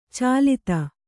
♪ cālita